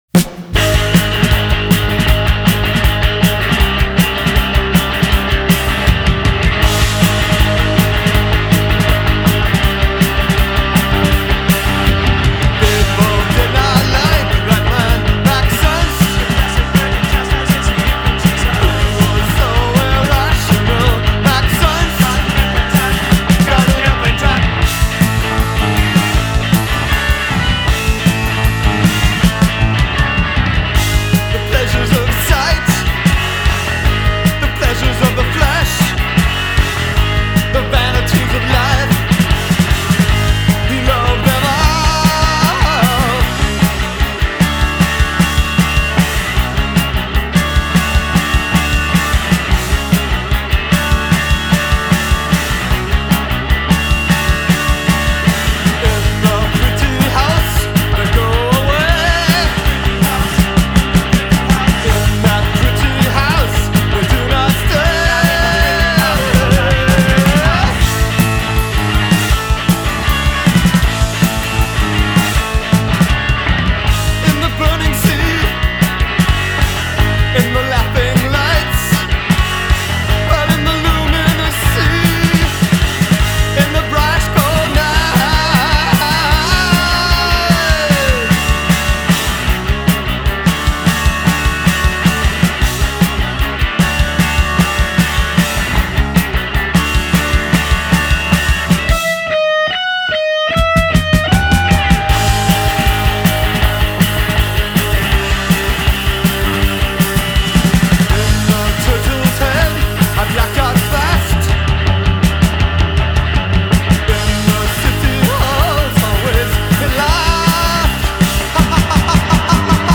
alt. rock